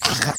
Minecraft Version Minecraft Version 1.21.5 Latest Release | Latest Snapshot 1.21.5 / assets / minecraft / sounds / entity / rabbit / attack1.ogg Compare With Compare With Latest Release | Latest Snapshot
attack1.ogg